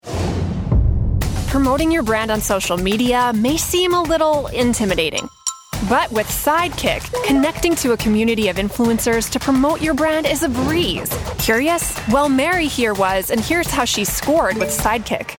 Explainer Voiceover Demo
• Conversational, Approachable - Sidekick Brand Promotion App